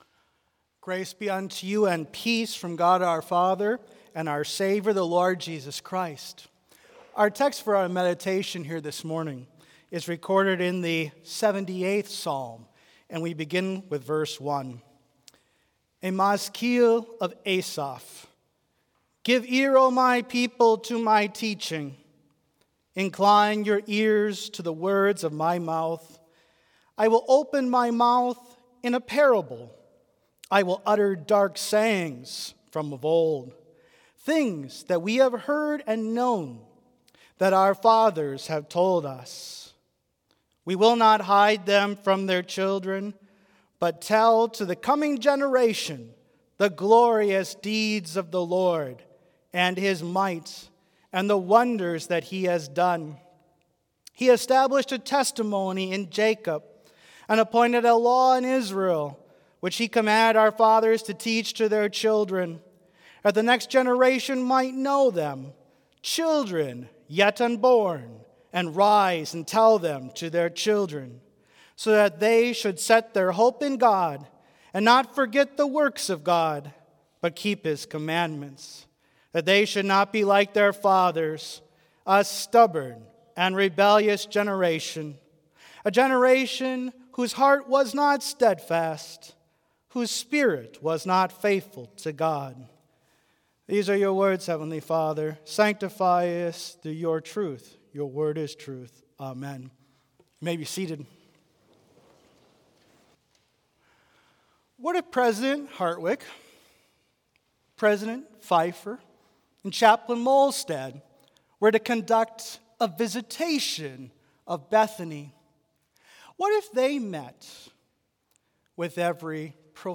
Complete service audio for Chapel - Monday, October 30, 2023
Hymn 251 - A Mighty Fortress is Our God
Devotion Prayer Hymn 586 - How Blest Are They Who Hear God's Word View Blessing Postlude